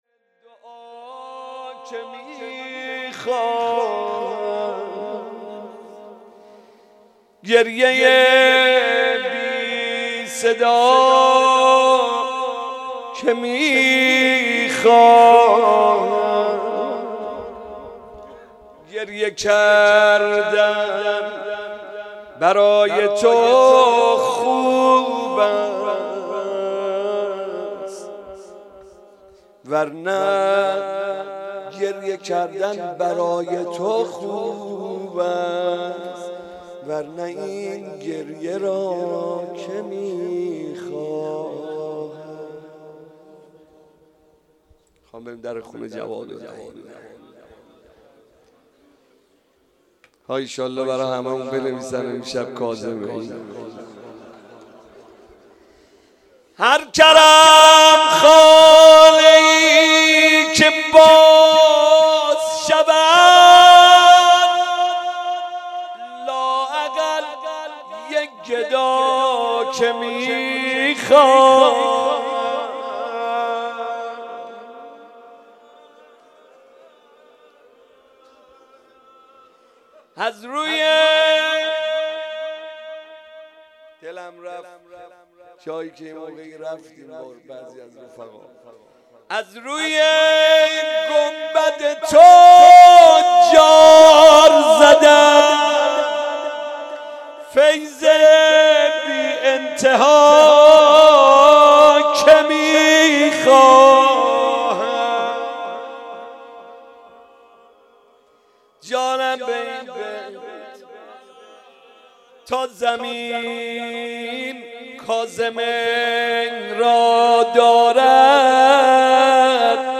روضه
روضه مناسبت : شب بیست و نهم رمضان سال انتشار